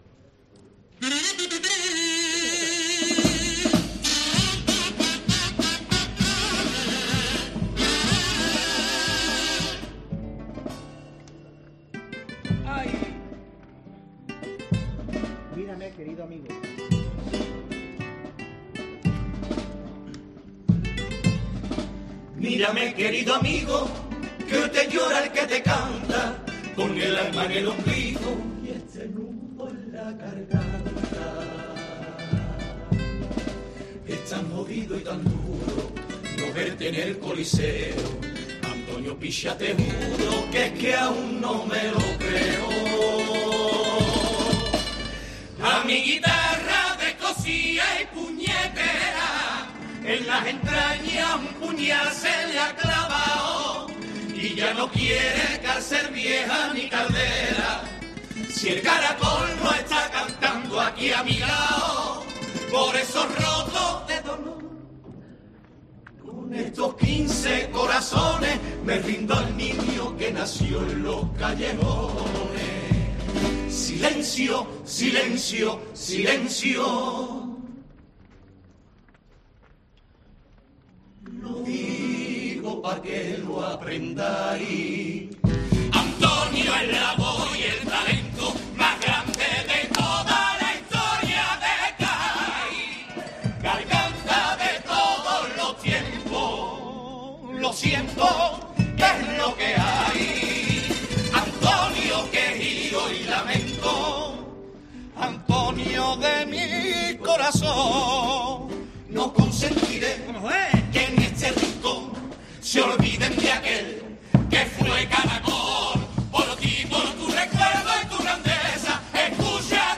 Pasodoble de Los Veleros a Caracol